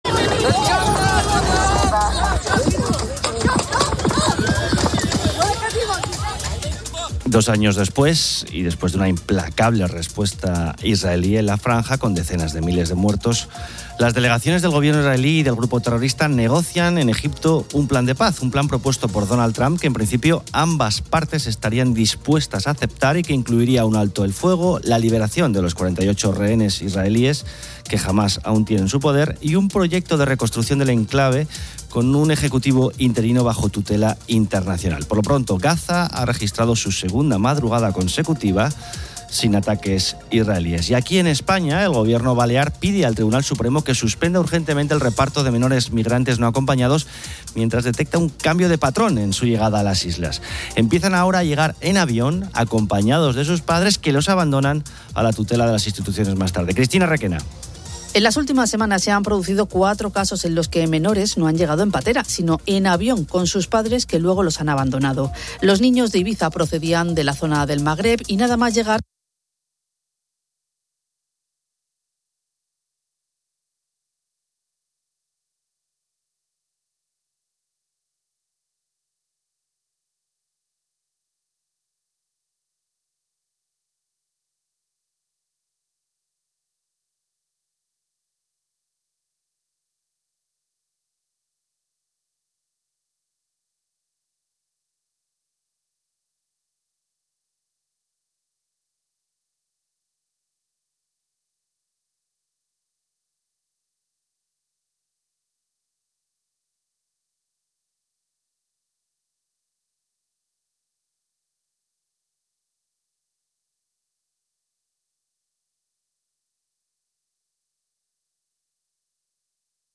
Un oyente sugiere un truco para los tacones, uniendo el tercer y cuarto dedo.